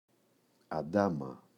αντάμα [a’ndama] – ΔΠΗ